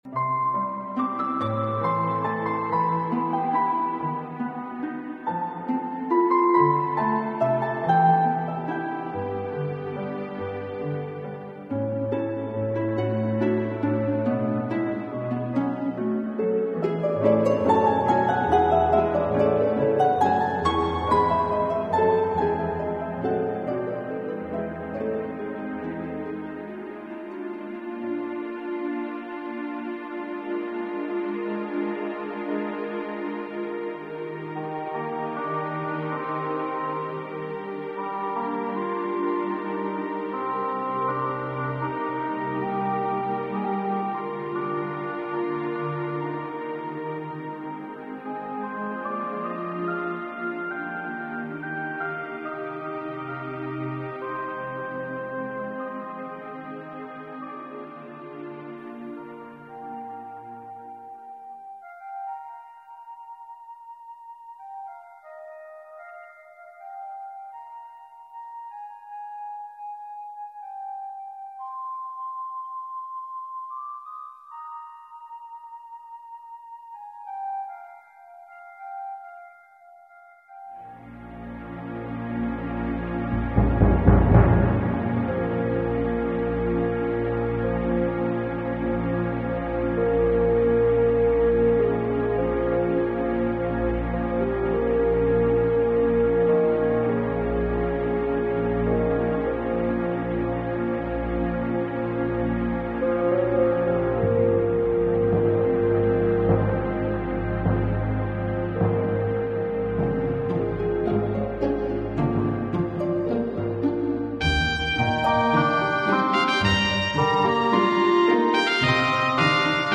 Orchestral & Instrumental Composer
I approached this play in a way I hadn't before, assigning specific instruments to key characters and composing them each a theme. Beatrice's theme is played by the harp, heard clearly in the opening music. Hero's theme on flute and Claudio's theme on English horn culminated in a duet that became their wedding procession.